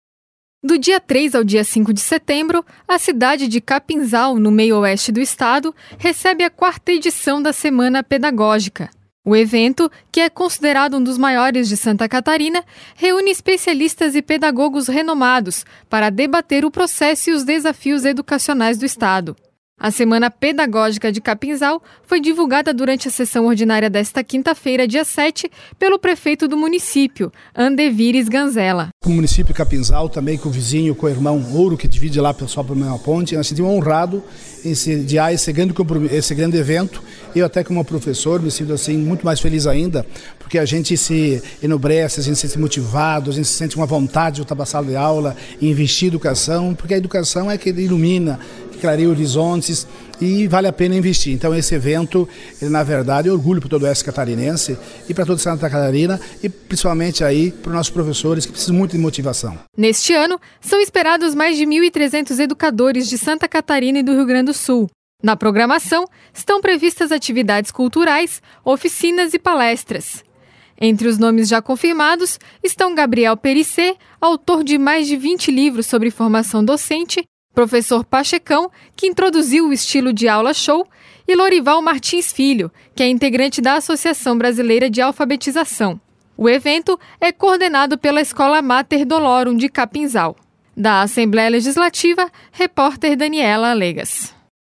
Entrevista: Andevir Isganzela, prefeito de Capinzal